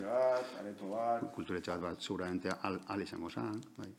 Sesión Ordinaria del pleno del Ayuntamiento celebrada el día 24 de noviembre de 2021, a las 19:00h.
Iñaki Tororikaguena Sarrionandia, Alcalde - EAJ-PNV (00:03:27)
Liher Aiartzaguena Bravo, Portavoz - EH BILDU (00:04:21)